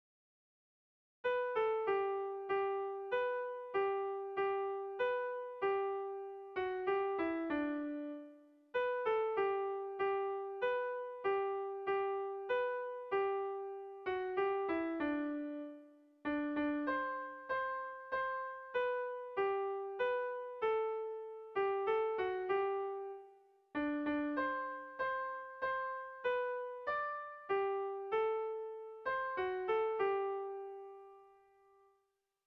Kontakizunezkoa
Zortziko txikia (hg) / Lau puntuko txikia (ip)
AAB1B2